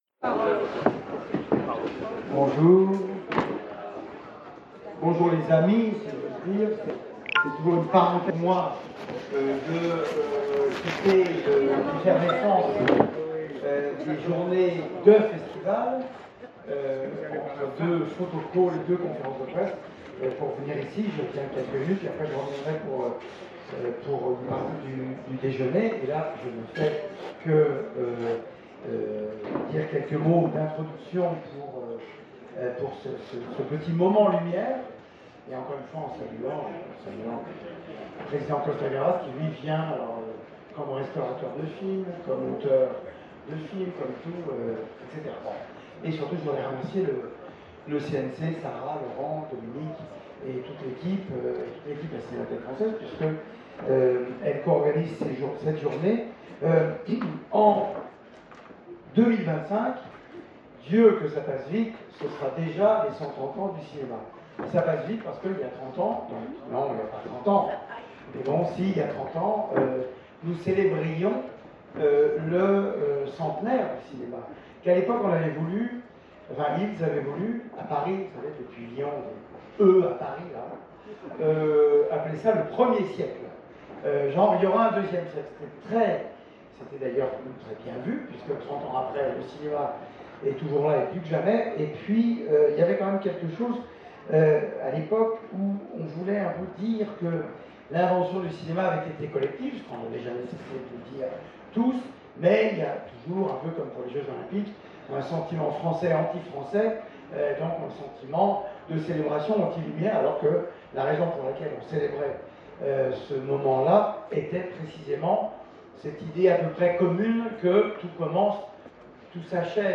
Etat des lieux des projets Lumière pour les 130 ans du cinématographe en 2025, en direct du Festival de Cannes 2024